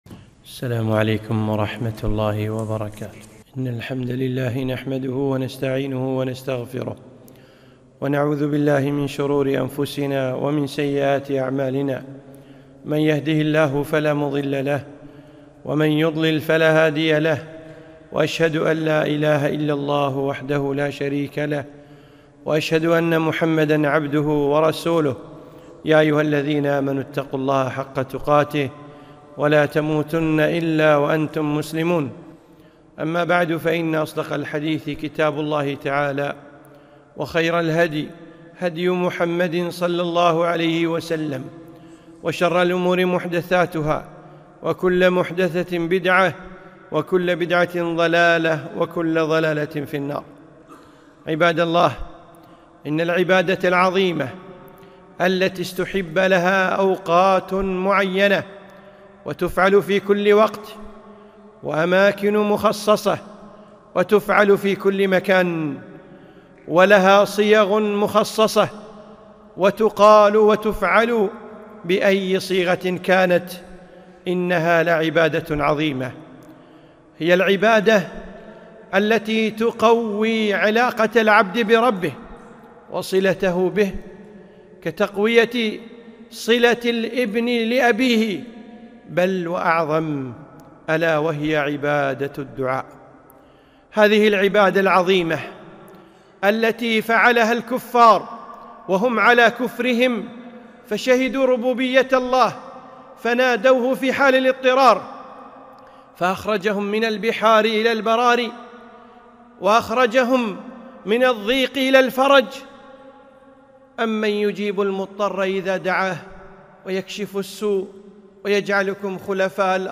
خطبة - العبادة العظيمة